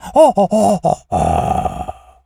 monkey_hurt_slow_death_01.wav